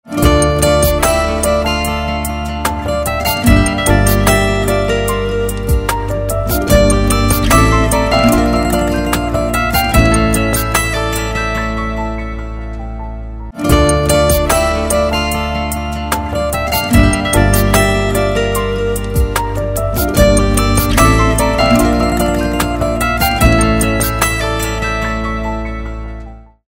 زنگ موبایل
رینگتون نرم و بیکلام